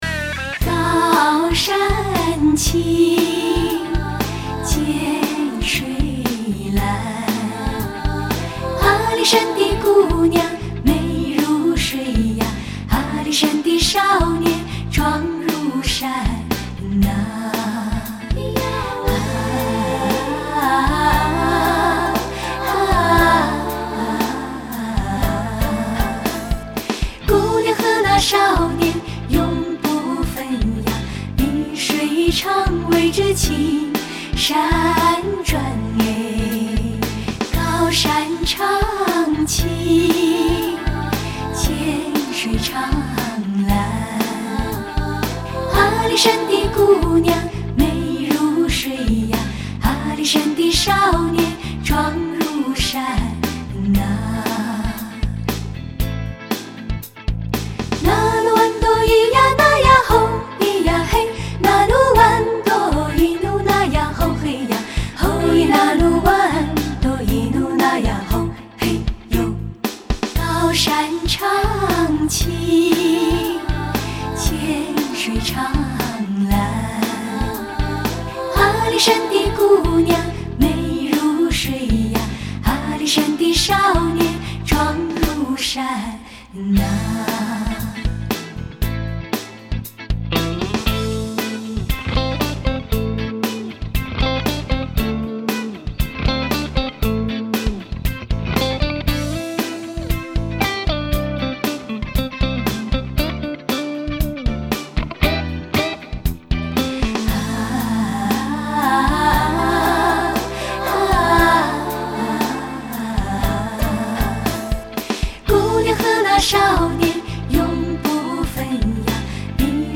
中国最早的和声组合之一。